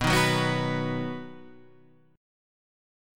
B Major